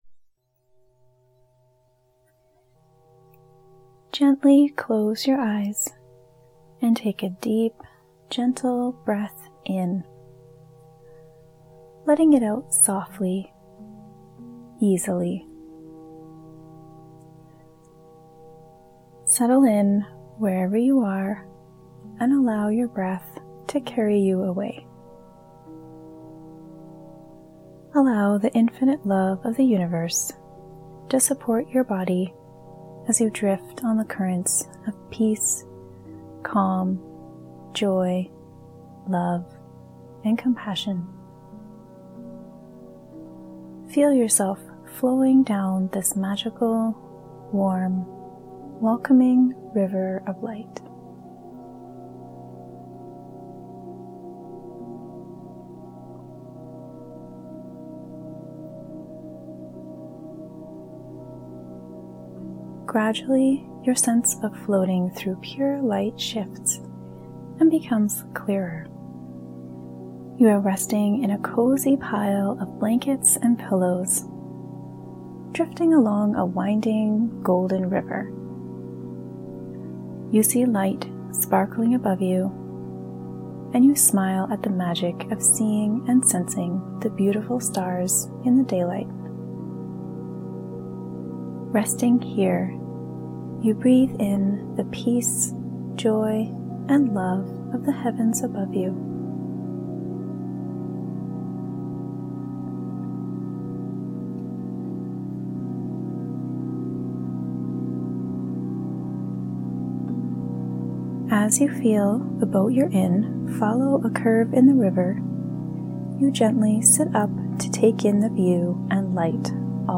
Holiday-Magic-Meditation-final.mp3